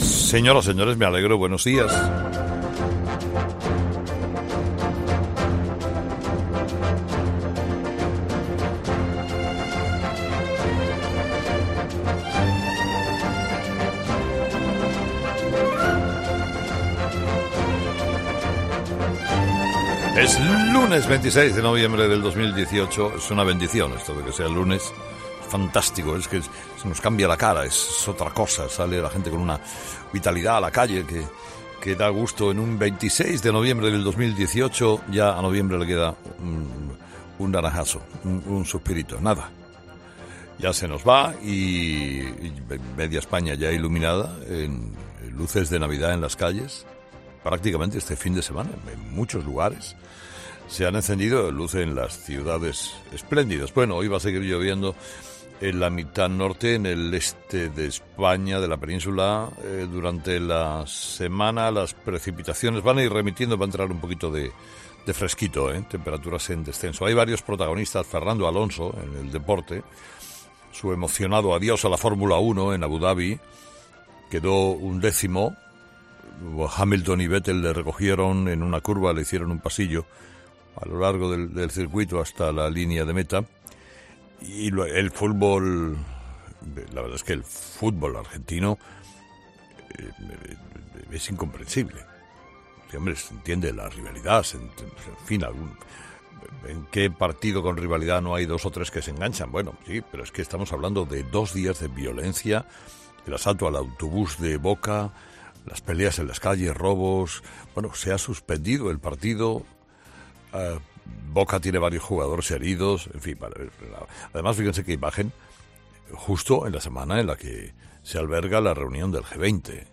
Monólogo de Herrera del lunes 26 de noviembre